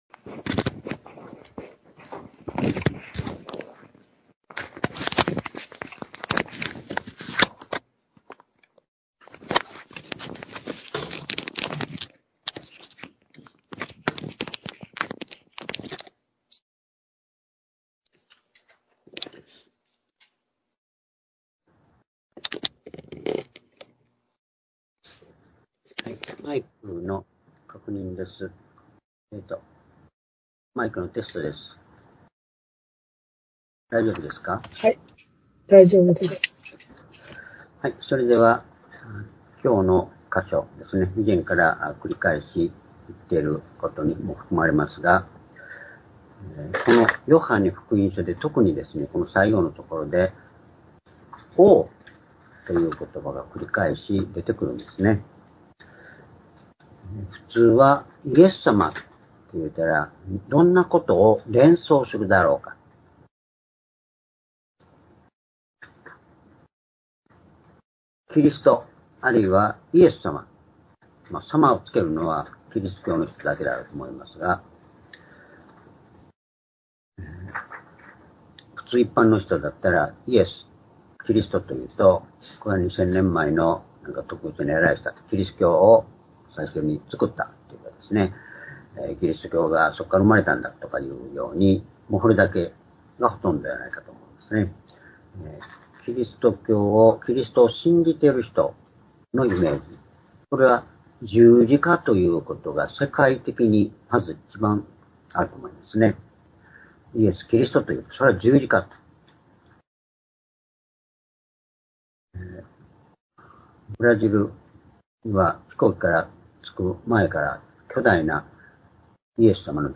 主日礼拝日時 ２０２４年12月１5日（主日礼拝） 聖書講話箇所 「王としてのキリスト」 ヨハネ１９の１７-２２ ※視聴できない場合は をクリックしてください。